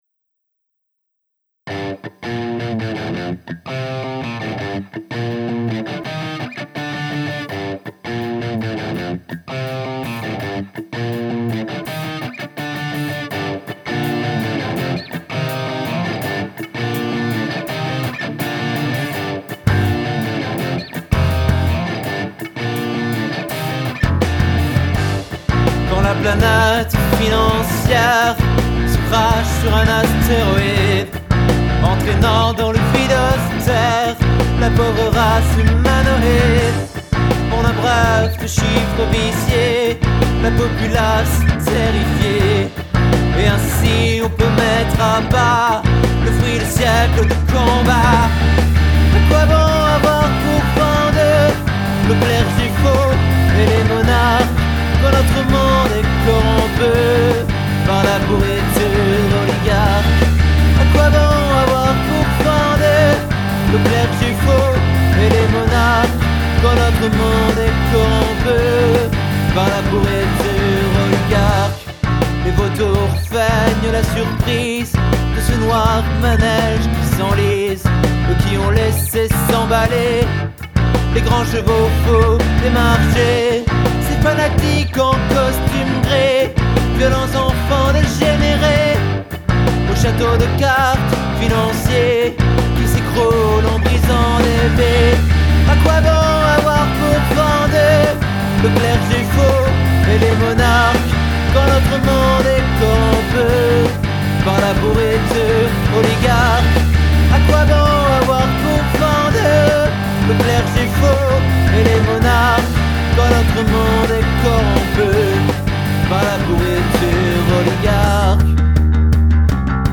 Ca s'appelle donc Farce Financière et c'est du rock !
Les riffs saccadés, la voix mi ecorchee mi joviale...
Beau chant de révolté je trouve! la voix en colère, et pleine d'espoir à d'autre moment, beau contraste, tu vis vraiemtn tes paroles et ton rythme, chapeau!
1. la production est bonne mais je trouve que la voix manque un peu de présence. Je m'explique: on a l'impression que le timbre de ta voix est étouffé par quelque chose, ça sonne étrangement plat alors qu'on sent que tu pousses pourtant bien derrière...
2. toujours sur la voix, comme c'est rock dans l'âme j'aurais plus attendu des quasi-beuglantes sur les refrains, parce que même si tu chantes juste, au moment où les guitares font monté la pression, la voix reste au même niveau qu'avant, et ça me paraît un peu "bizarre".